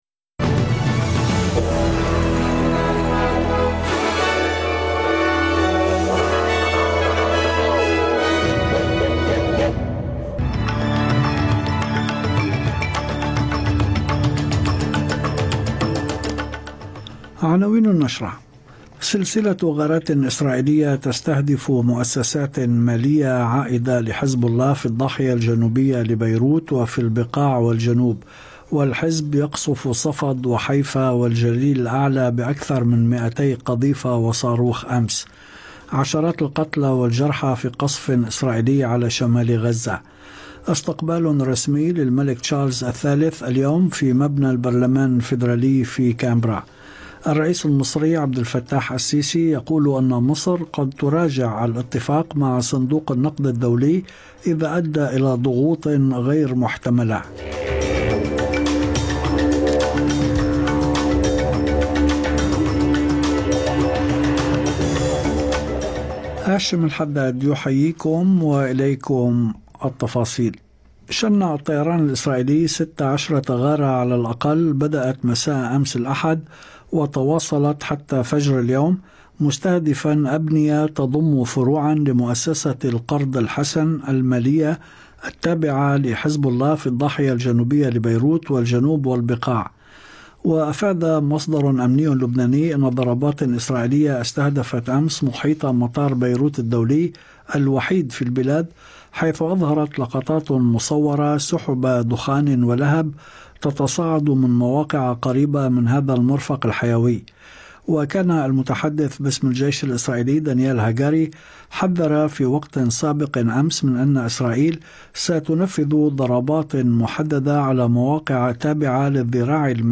نشرة أخبار المساء 21/10/2024